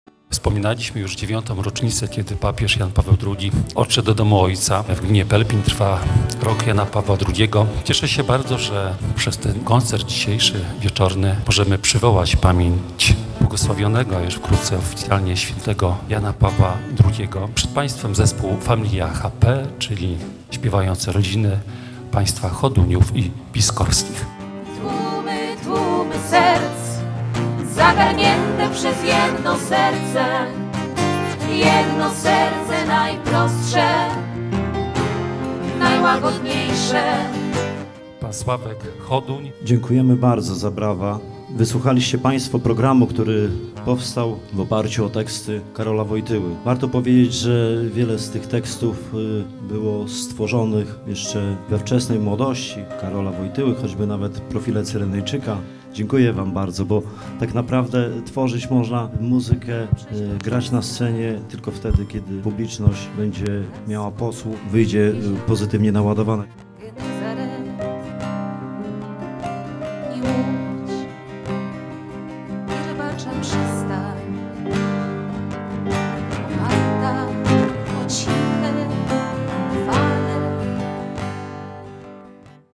Relacja Radia Głos (2,586 kB)